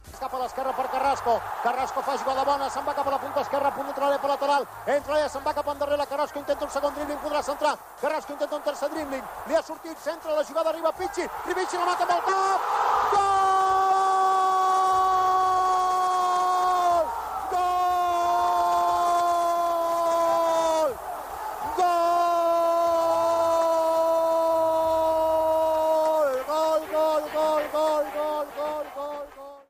Narració del gol d'Ángel "Pichi" Alonso, al Goteborg a la Copa d'Europa de futbol masculí
Esportiu